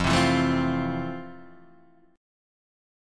E#maj7 chord